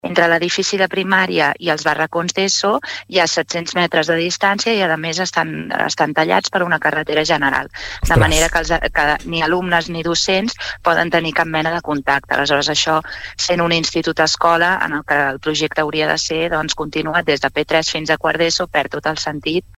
VergesEntrevistes Supermatí